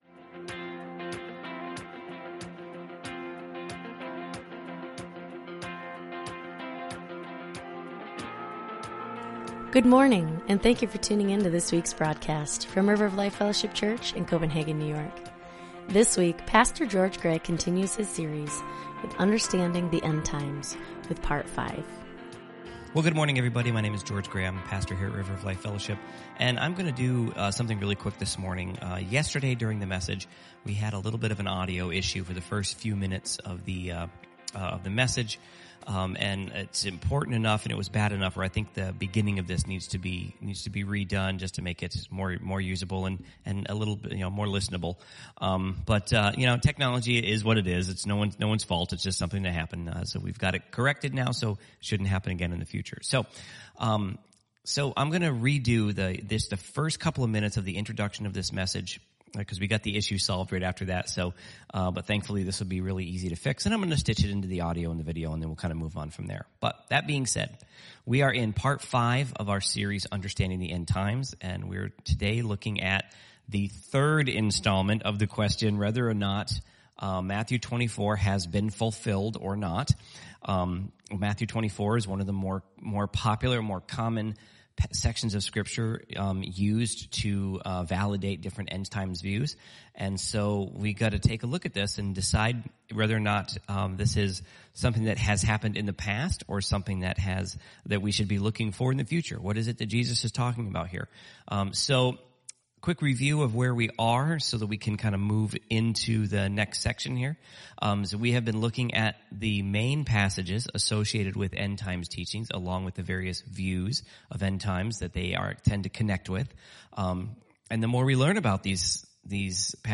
Sermons | River of Life Fellowship Church